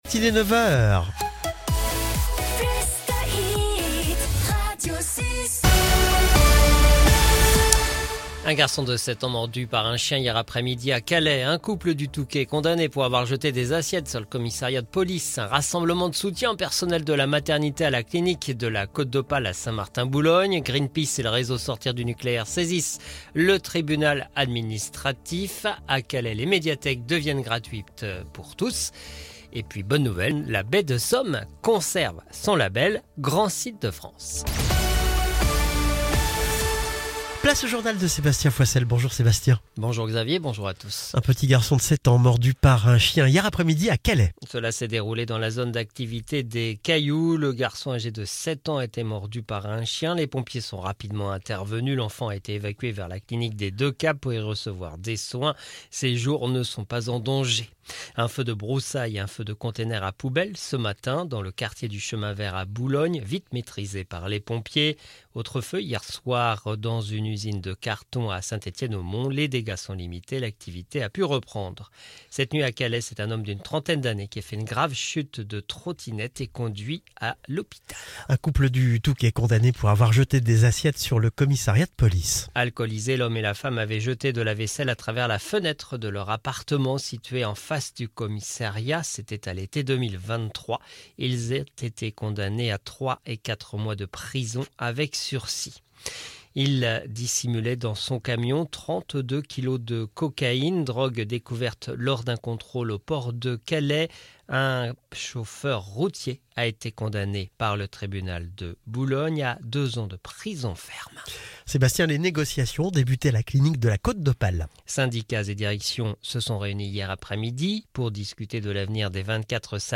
Le journal de jeudi 10 avril 2025